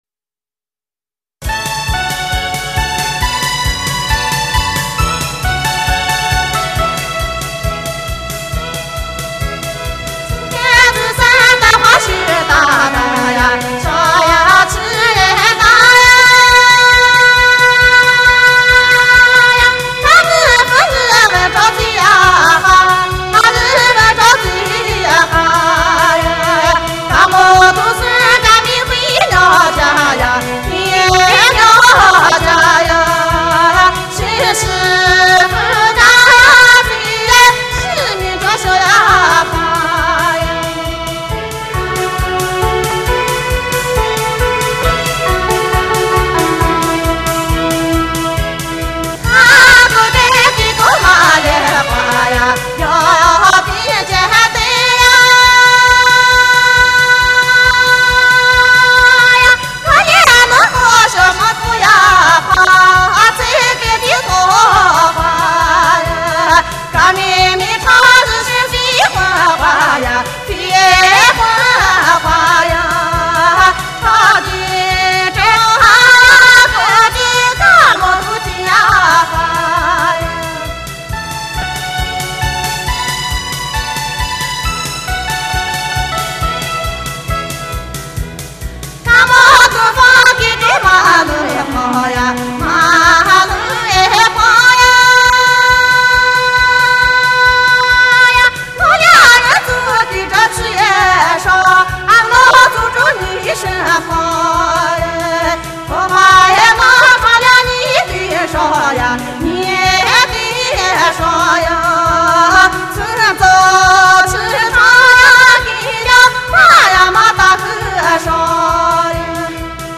青海花儿